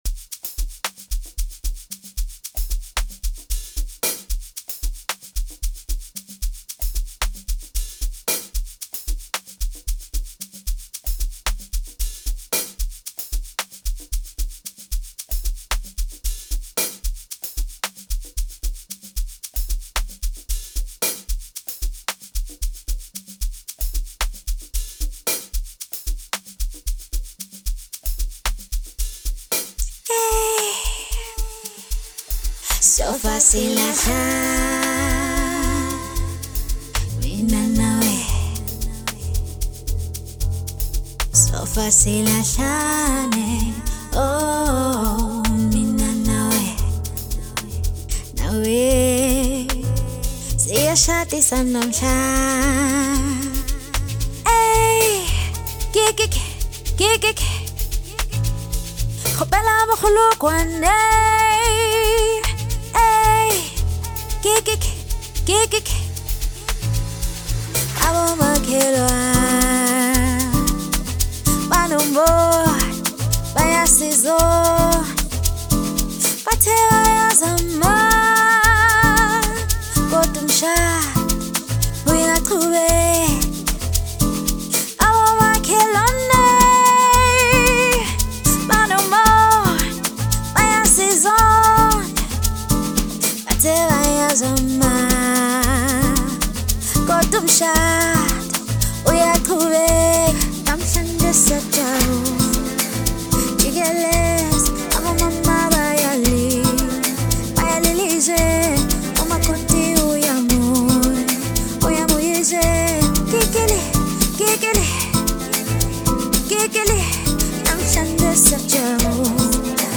Categoria: Amapiano